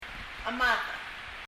chemadech[! əma(:)ða !]green